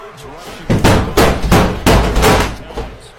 flightreacts banging door sound effect Meme Sound Effect
flightreacts banging door sound effect.mp3